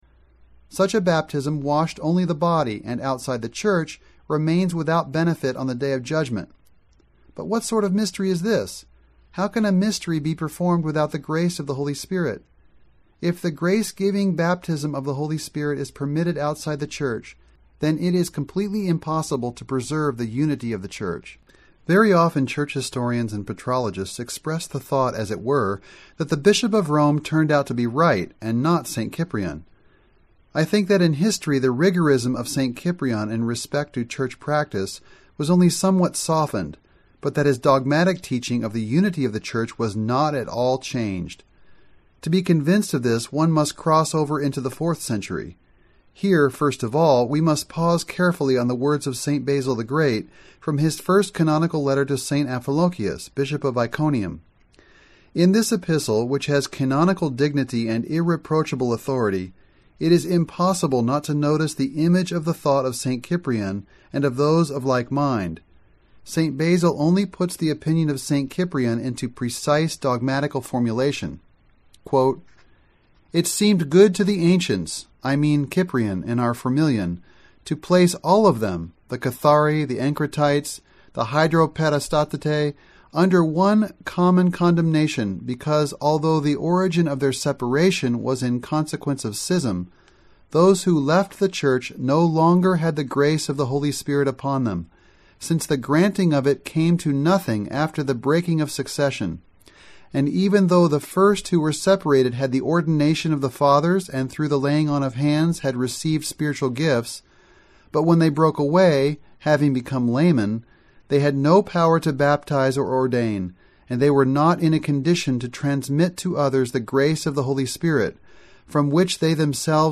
This is the first audio book offered by the Orthodox Christian Information Center.